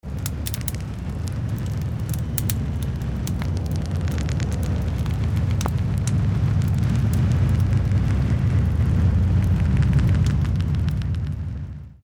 Age of Mythology Cutscenes [2/4] 11.55 Очень понравился звук похожий на заглушеные раскаты, возможно выстрелов. Возможно он реверсированный.